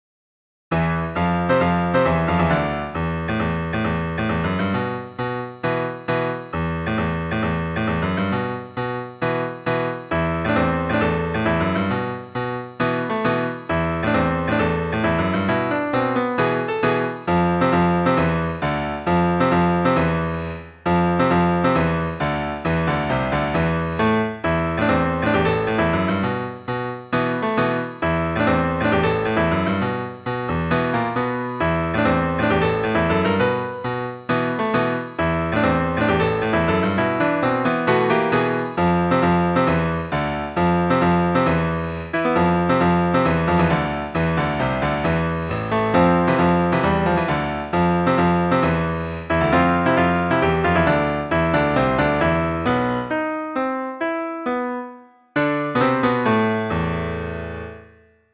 Another quickie; this time with a Russian flavor.